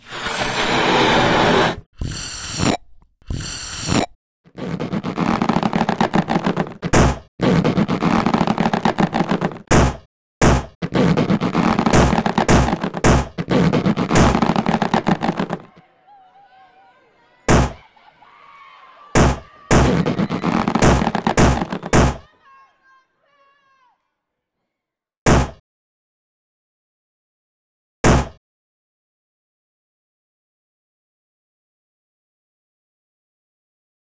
Landing machine /balloons, a chair, soil, sound of popping balloons/ balloons reaching gradually a pile of soil
BALLOONS.wav